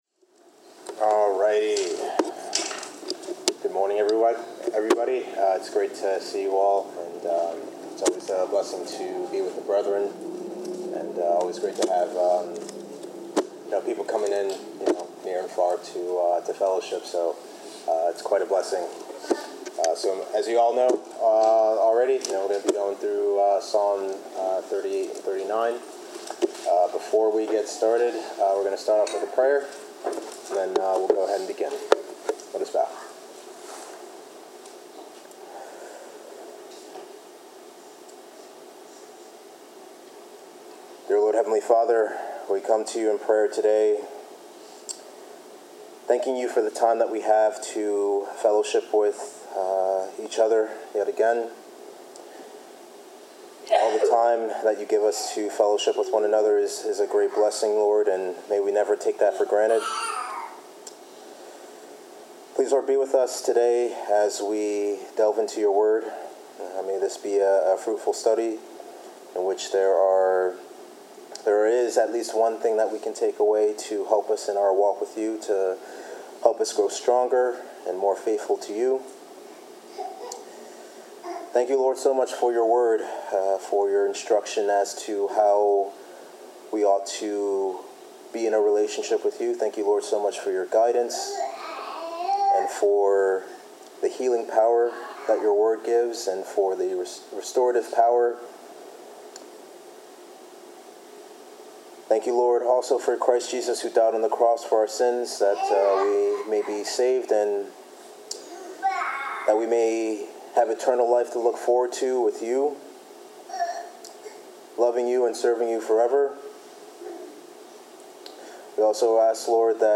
Bible Class: Psalms 38-39
Service Type: Bible Class